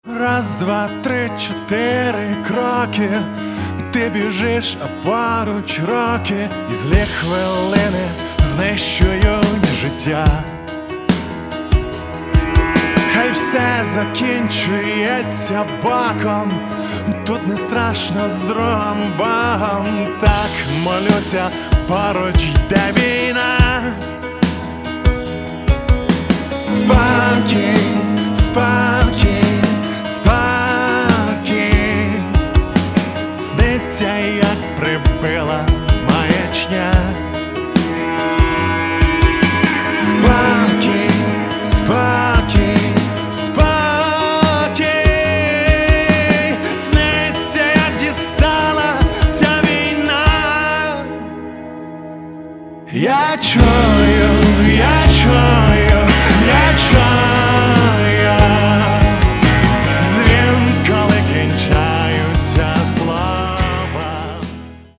Каталог -> Рок и альтернатива -> Просто рок